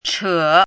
“扯”读音
chě
扯字注音：ㄔㄜˇ
国际音标：tʂʰɤ˨˩˦
chě.mp3